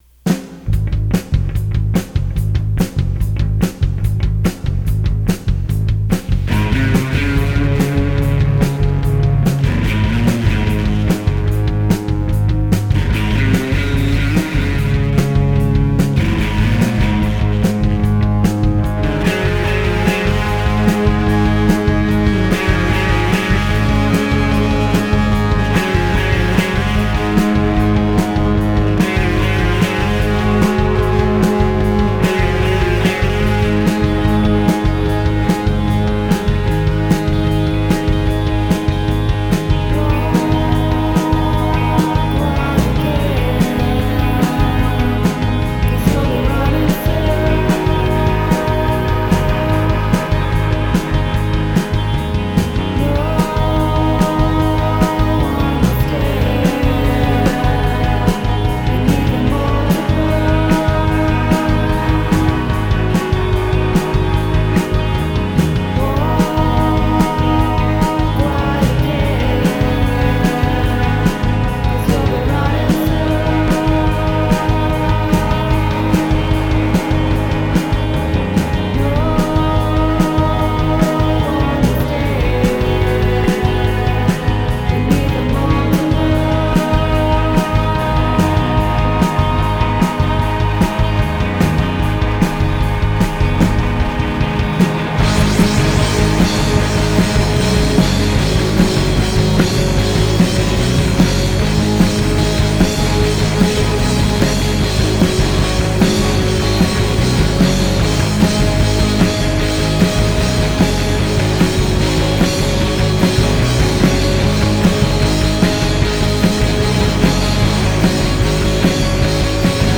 cosmic-rock
It sounds intense.